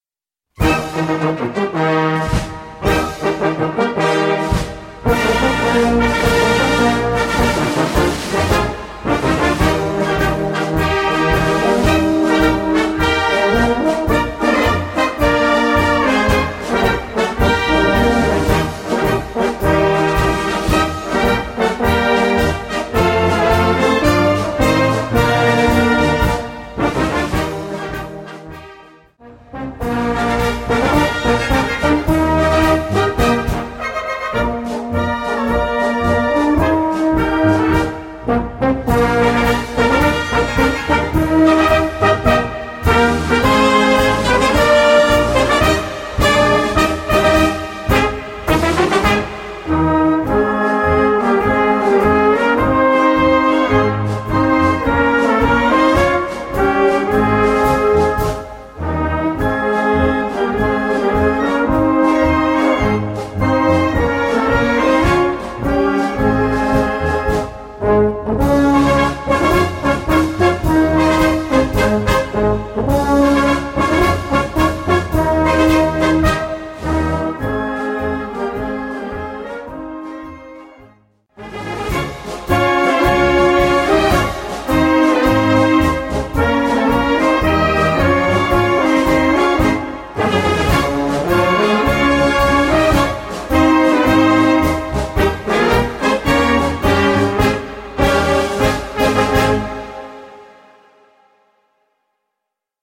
Marschbuchformat
schwungvollen Marsch